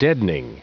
Prononciation du mot deadening en anglais (fichier audio)
Prononciation du mot : deadening